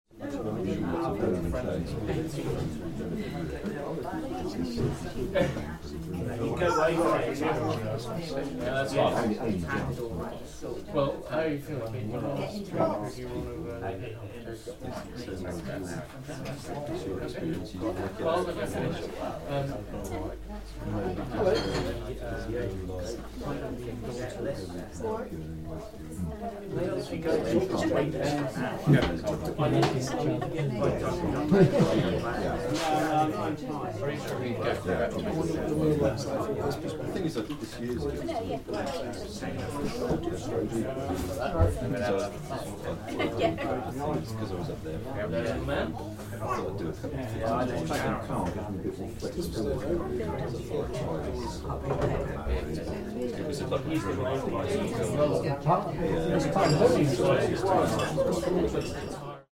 convivial chatter in the Square and Compass Inn